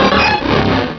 Cri d'Élecsprint dans Pokémon Rubis et Saphir.